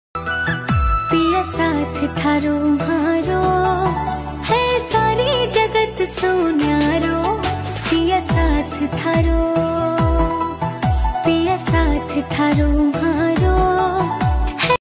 Rajasthani song